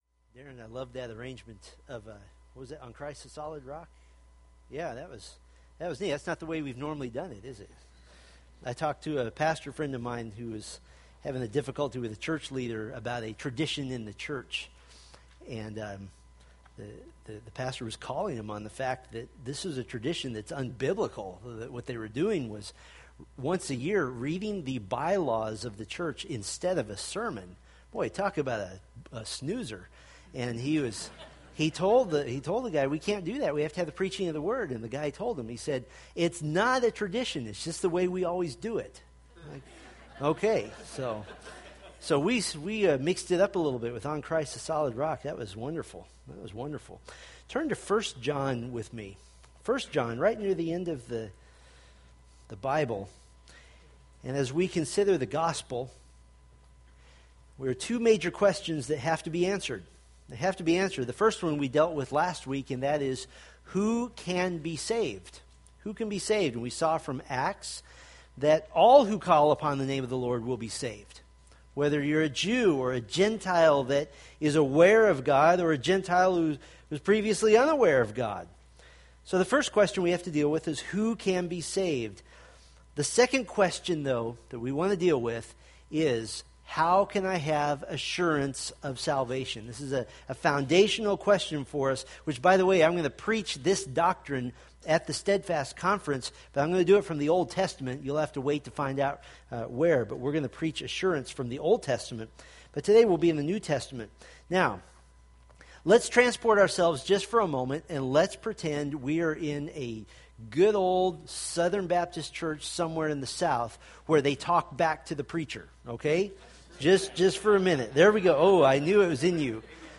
1 John Sermon Series